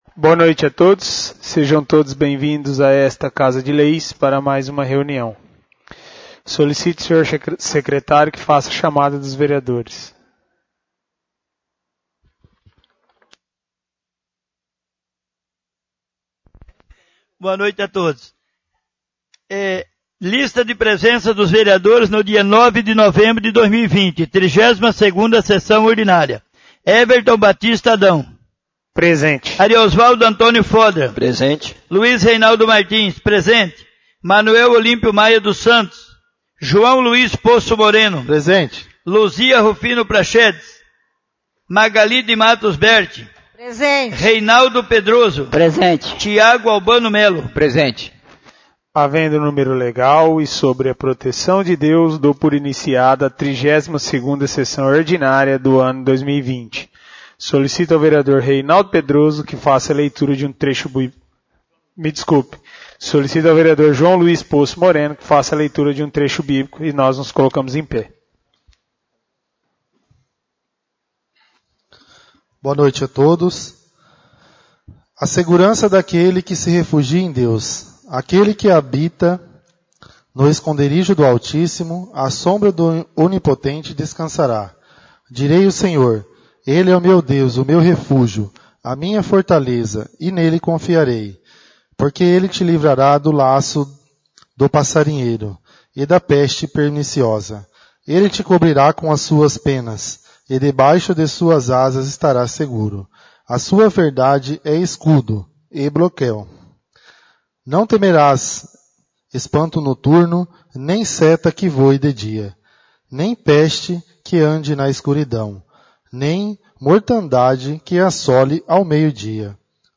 32° Sessão Ordinária